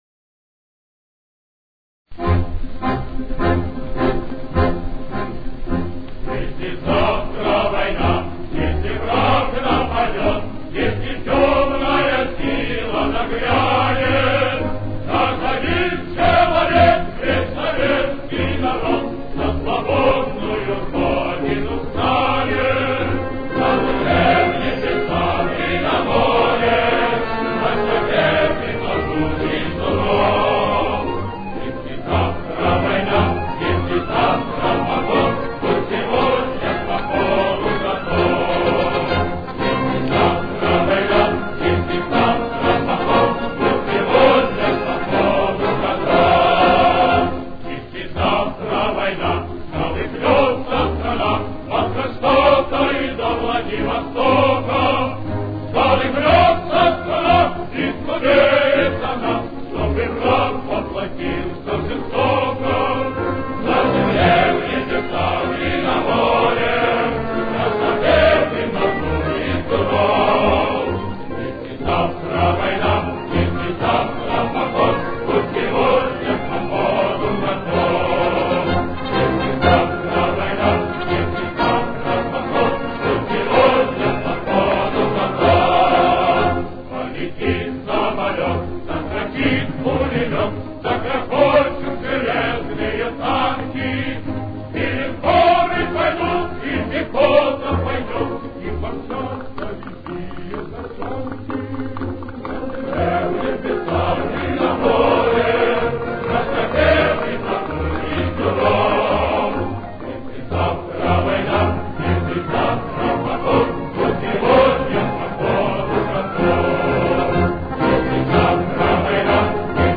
Фа минор. Темп: 122.